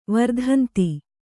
♪ mardhanti